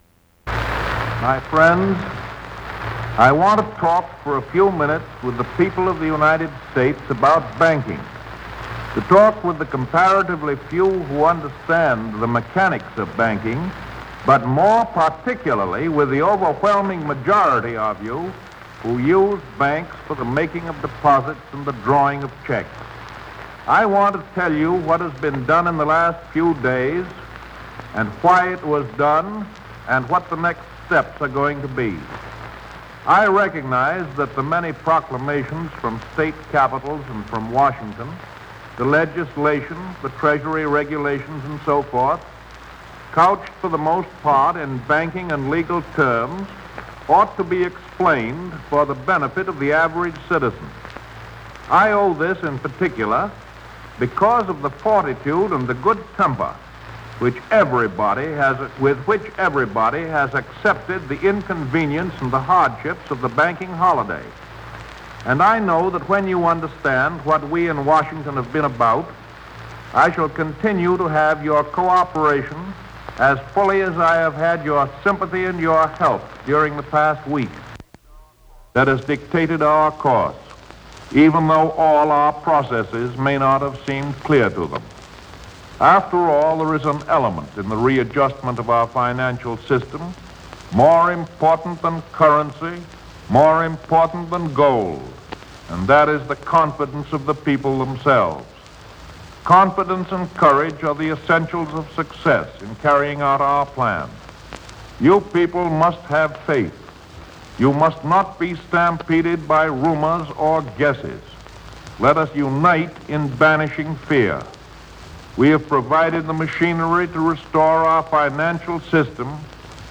Fireside chat. 1933 March 12
U.S. President Franklin D. Roosevelt talks about the banking situation in the U.S. The opening and closing statements are part of this broadcast.
Broadcast 1933 March 12.